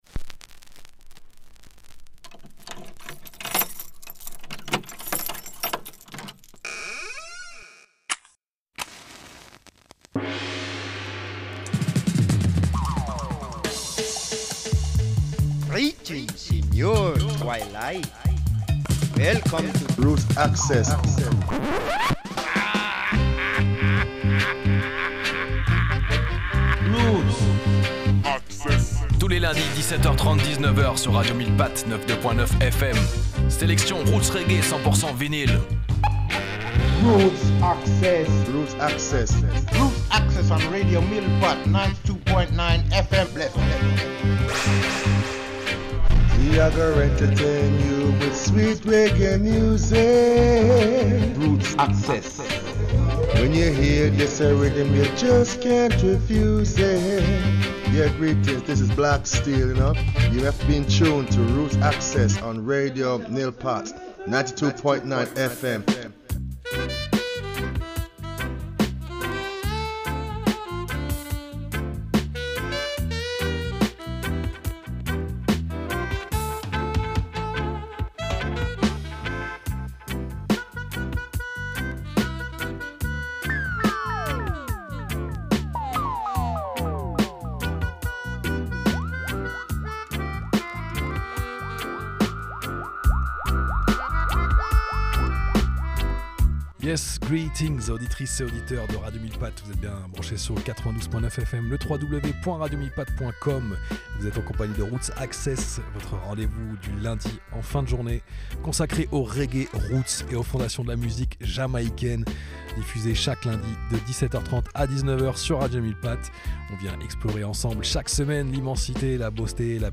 Reggae Radio Show